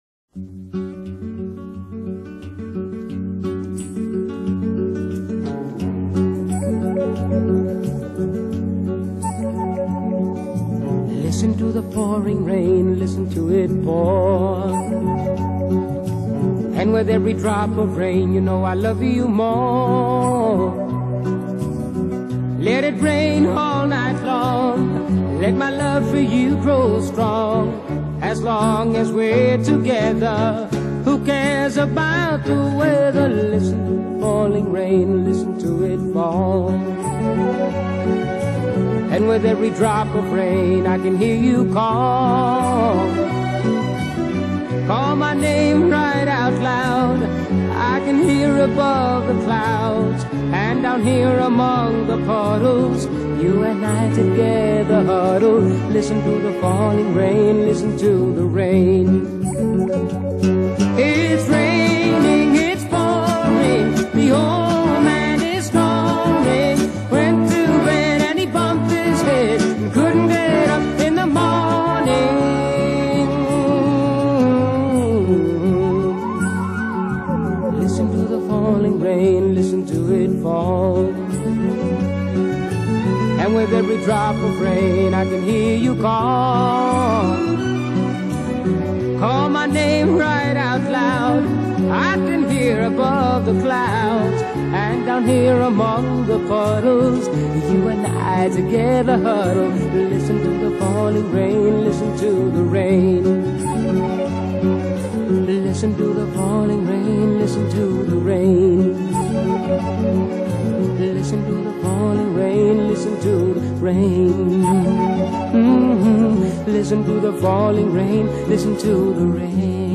Genre: Latin American, Acoustic Guitar